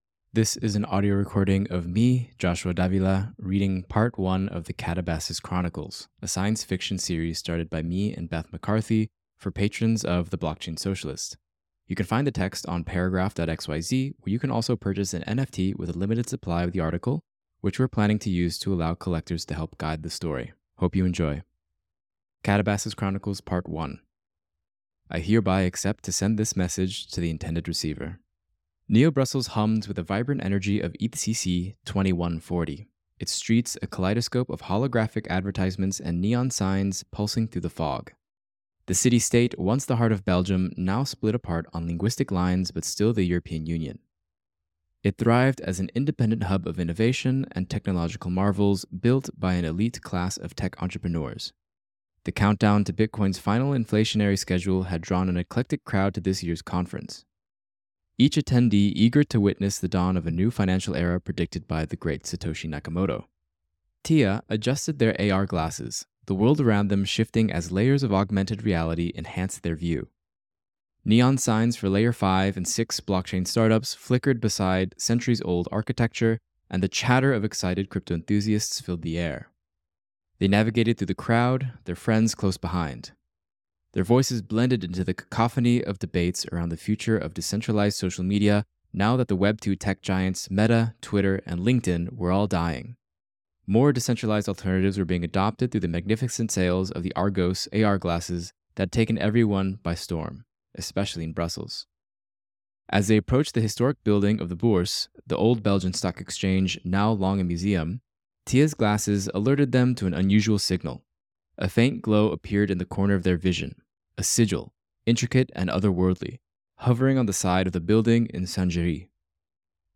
Don’t forget to Subscribe to get the next installments in your inbox :) If you liked the song in the audio, you can find it here .